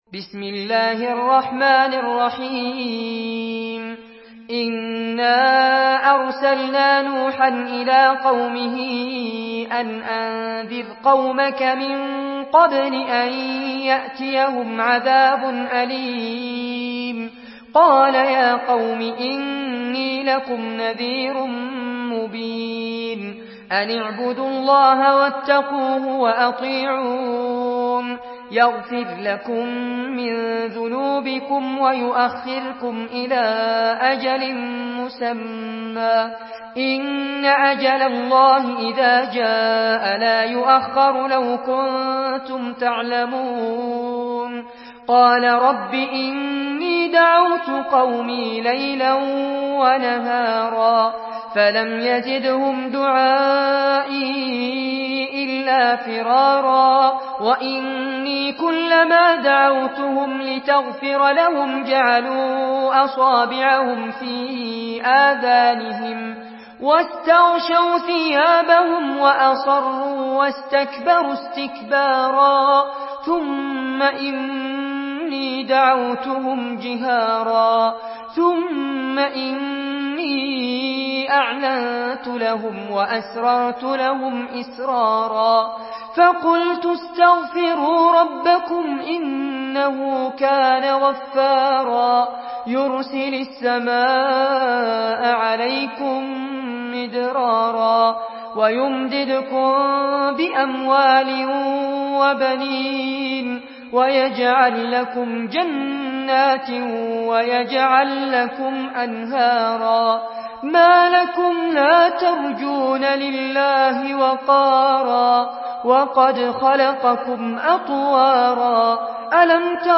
Surah نوح MP3 by فارس عباد in حفص عن عاصم narration.
مرتل